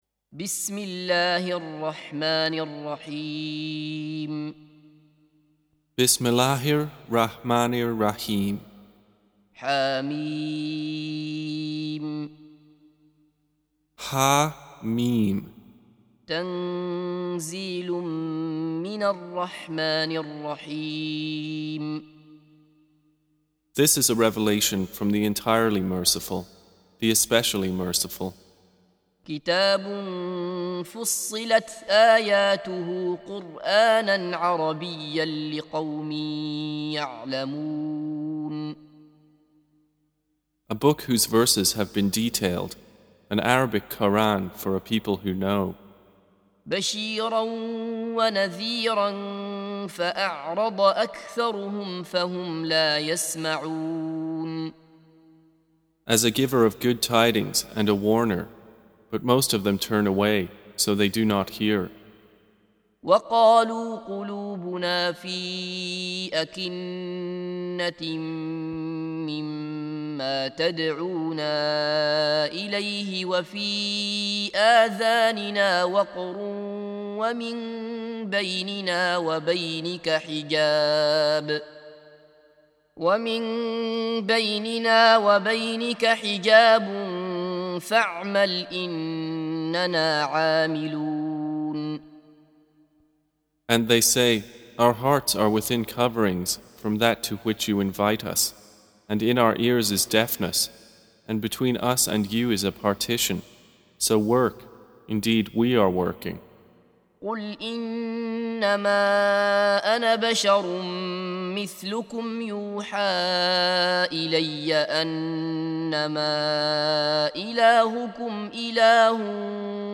Surah Repeating تكرار السورة Download Surah حمّل السورة Reciting Mutarjamah Translation Audio for 41.